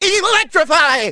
Electro Wiz Attack Sound Effect Free Download